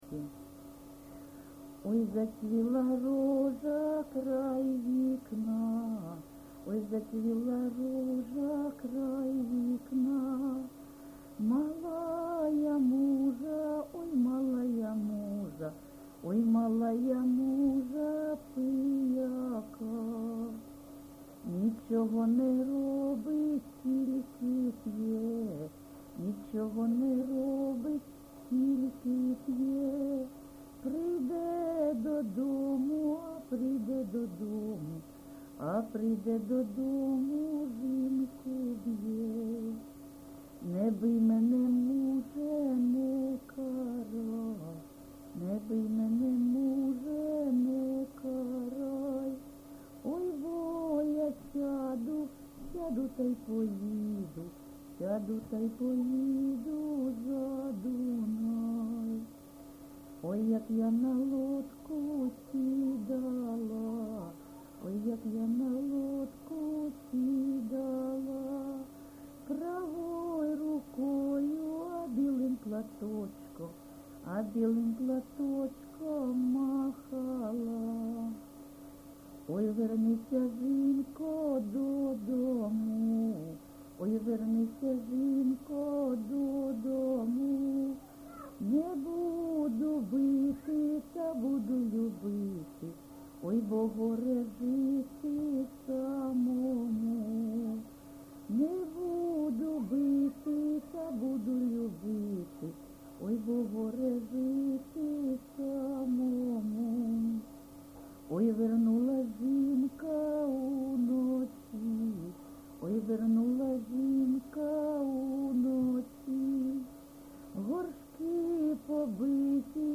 ЖанрПісні з особистого та родинного життя
Місце записум. Бахмут, Бахмутський район, Донецька обл., Україна, Слобожанщина